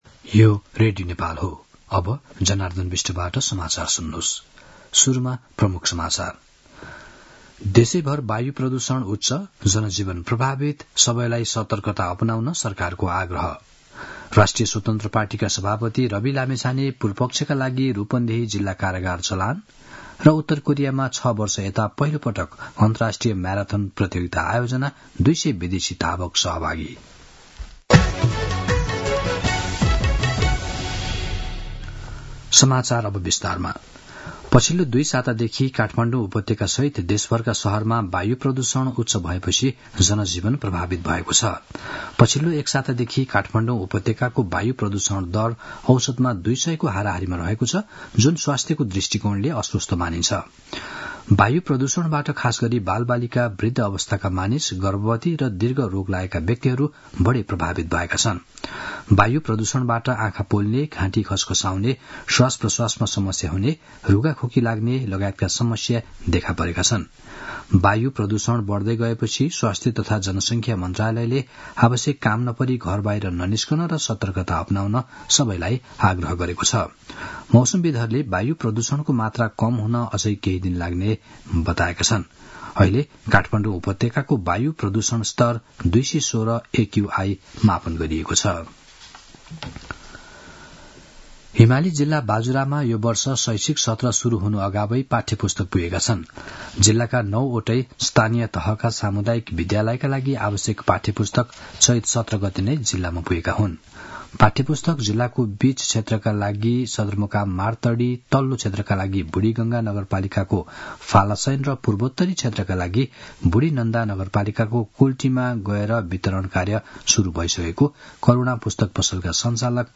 दिउँसो ३ बजेको नेपाली समाचार : २५ चैत , २०८१
3-pm-news-1-1.mp3